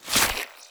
x1_battle_hit_blade_2.wav